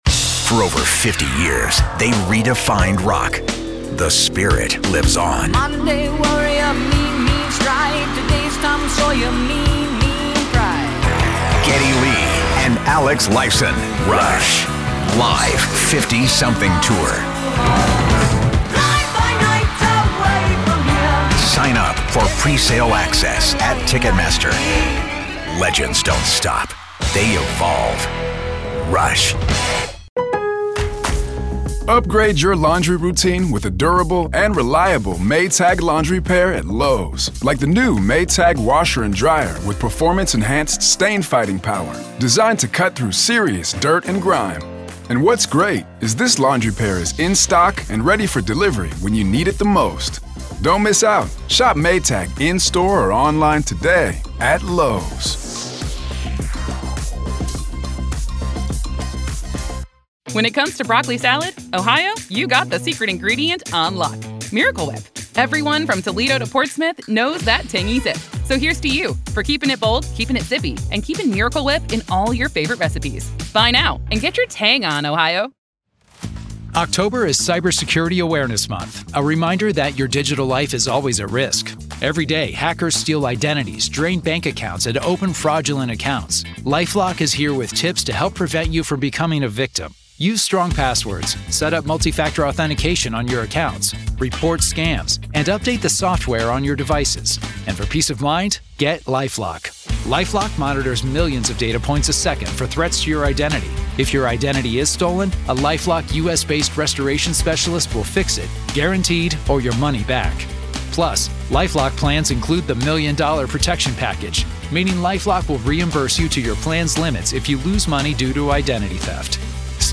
True Crime Today | Daily True Crime News & Interviews / How Will The Trump Shooting Affect Other Political Gatherings This Year?